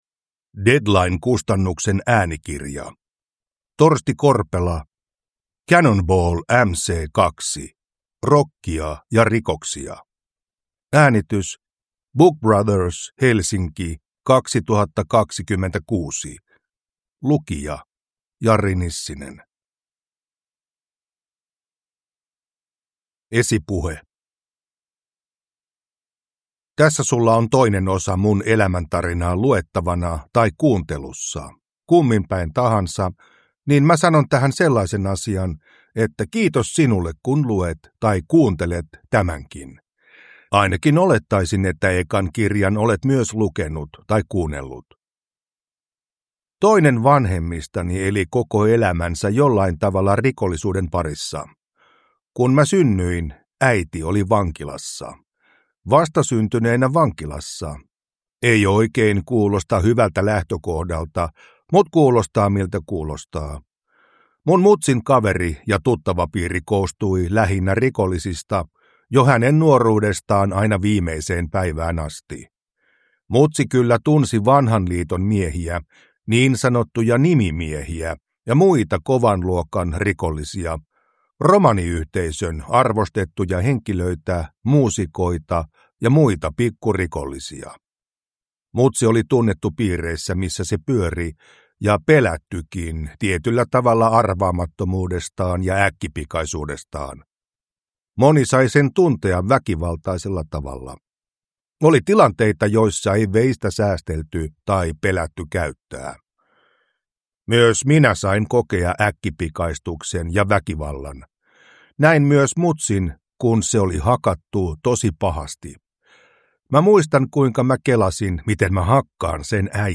Cannonball MC 2 – Ljudbok